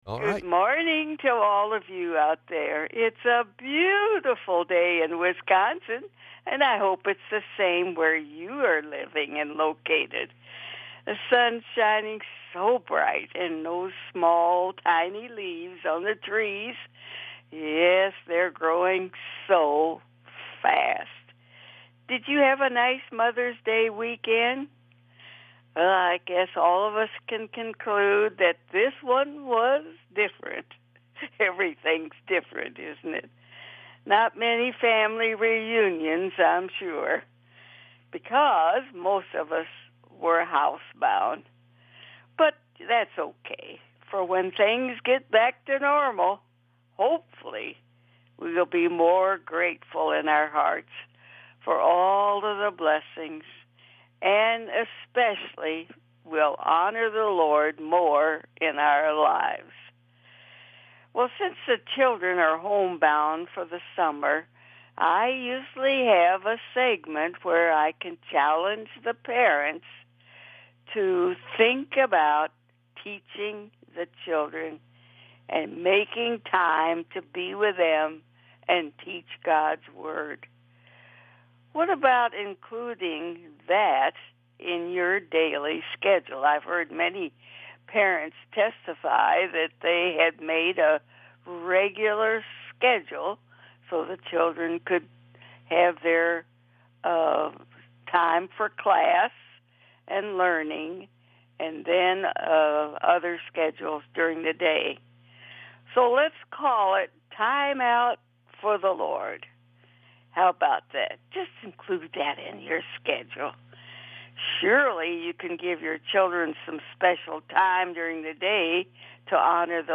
The show is heard weekdays live at 5:45 pm and 6:45 am on WWIB On April 21st of 2012 right hander Philip Humber became only the 21st Pitcher in Major League History to throw a perfect game when he shut down the Seattle Mariners at SAFECO field.